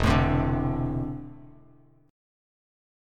E7sus4#5 chord